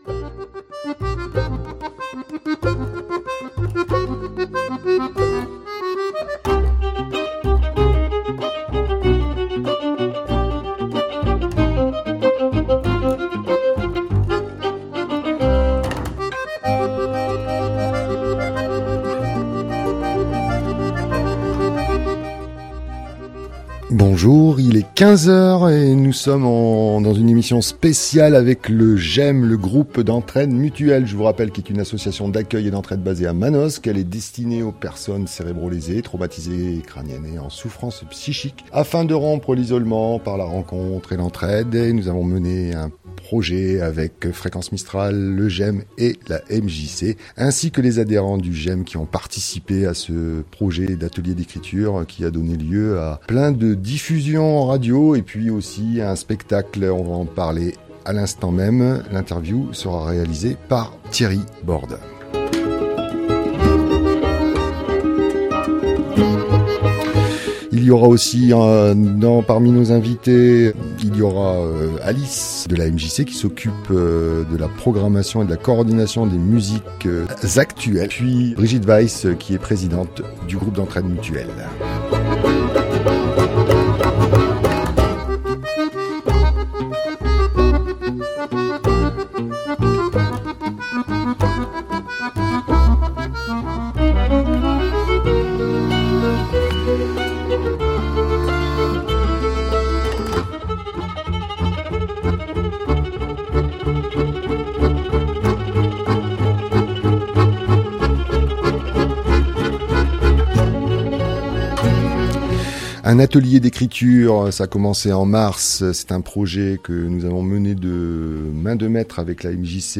Gem Ma Vie en musique et lecture en direct sur Mistral